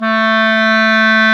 WND  CLAR 05.wav